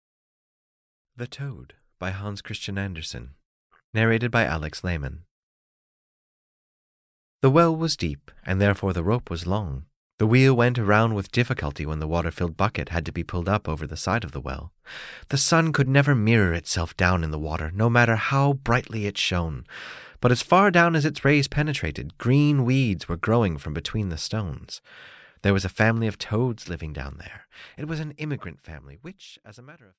The Toad (EN) audiokniha
Ukázka z knihy